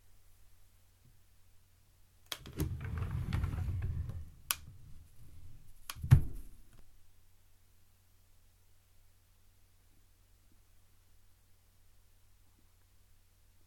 Slow/Open drawer 4
Description - Wooden drawer, opens, slowly, drags,